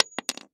LockpickDrop_03.wav